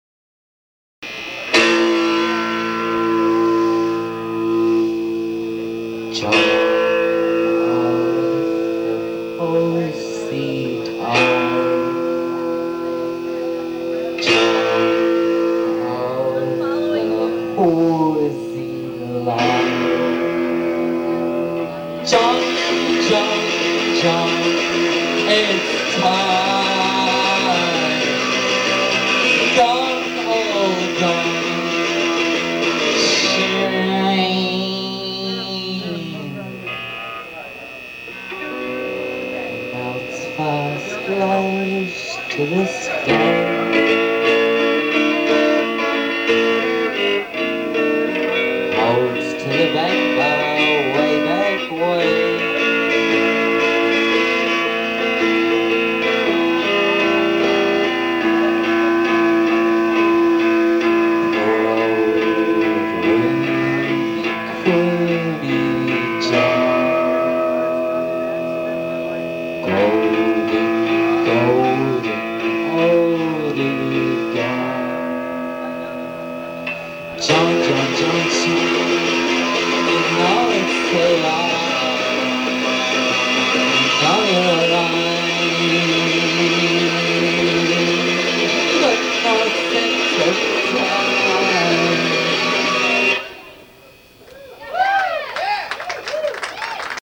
This was recorded in the audience at the Khyber on 8/19/93.